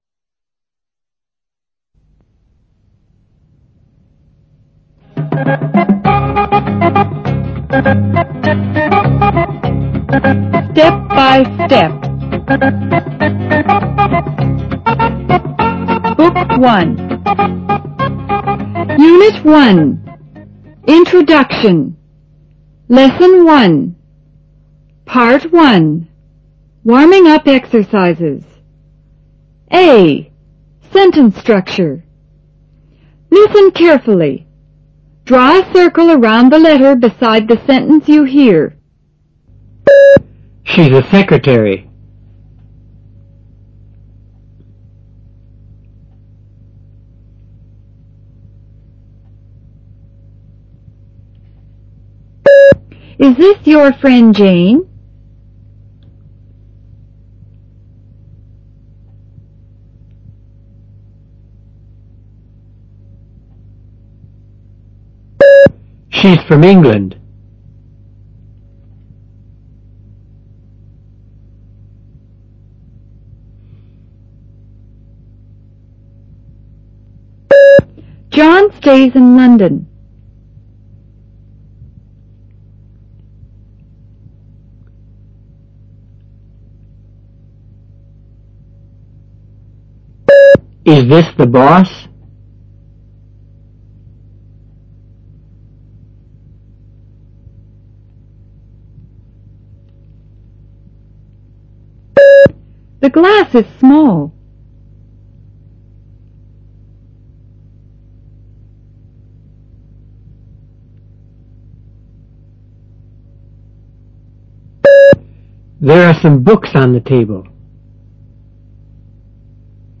Part I Warming-up Exercises
Directions: You are going to hear some sentences chosen from the comprehension material in this lesson.